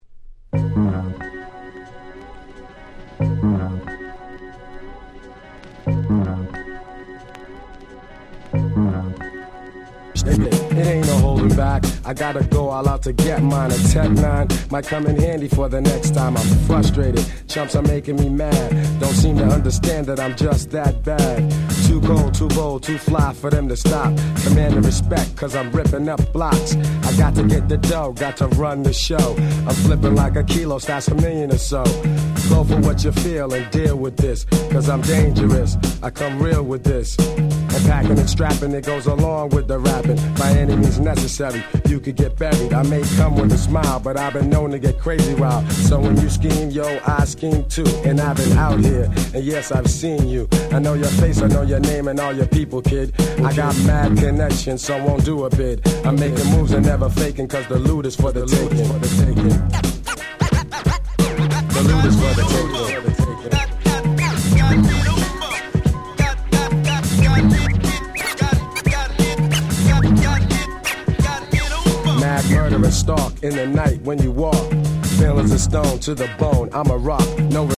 92' Super Classic Hip Hop !!